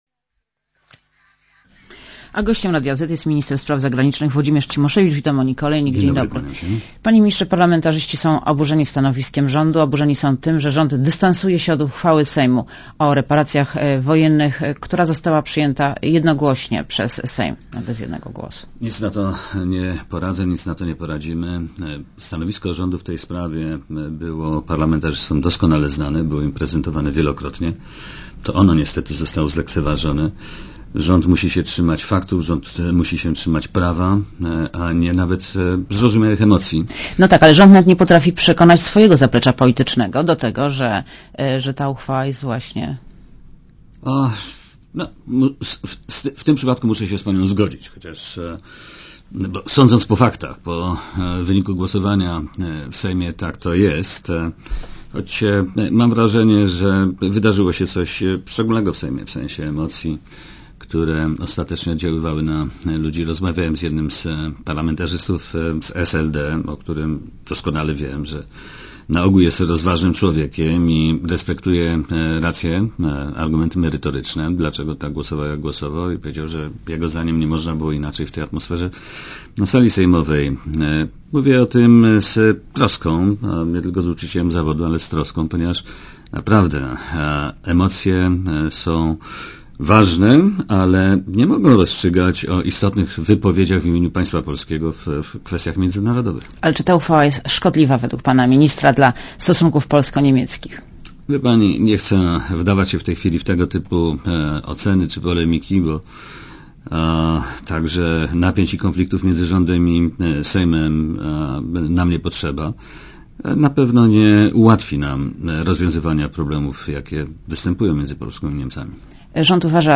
Posłuchaj wywiadu Gościem Radia Zet jest Włodzimierz Cimoszewicz , minister spraw zagranicznych.